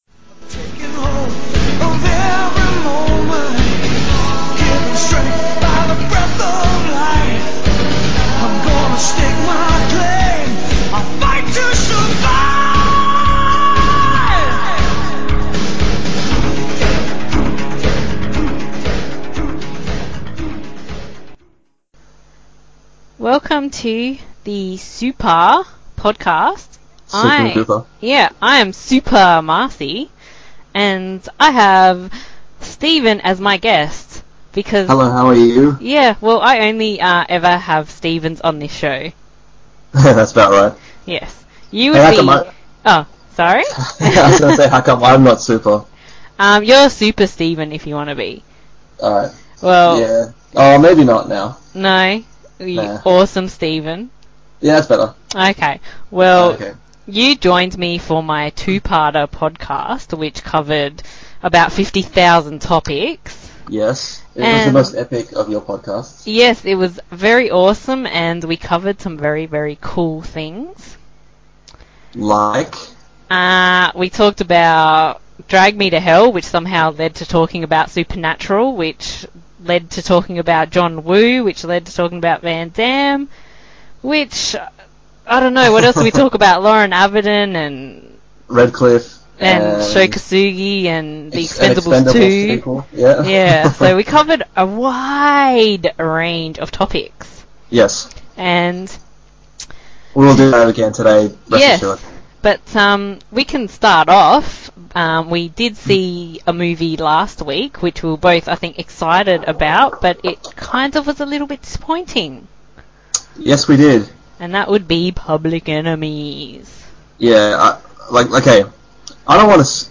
Folks this is a no holds barred film discussion, we review and discuss countless things, no holding back!